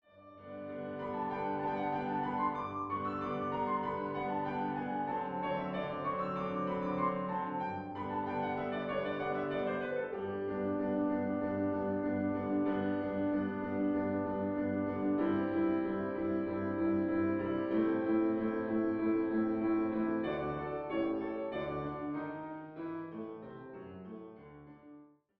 Klavier-Sound